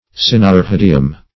Meaning of cynarrhodium. cynarrhodium synonyms, pronunciation, spelling and more from Free Dictionary.